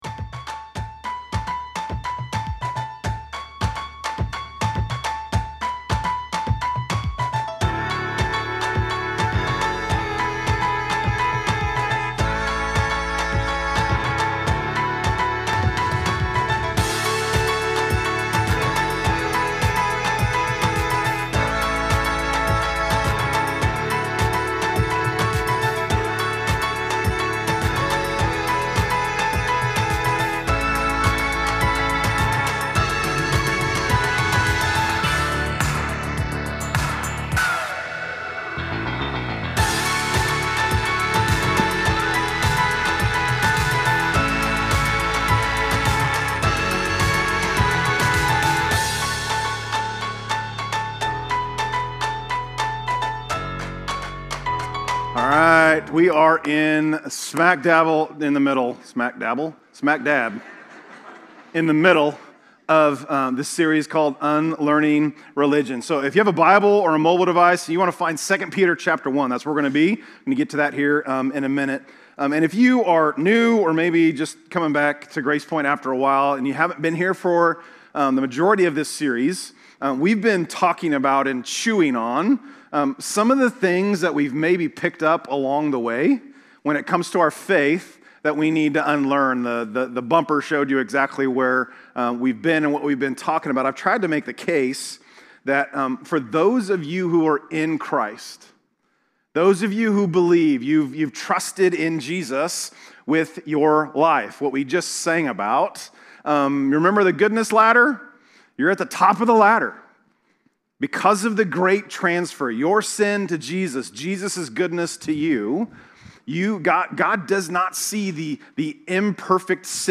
This podcast features the teachings from our weekend worship services. They are practical and gospel-centered, meant to encourage you to come to know Christ and follow him.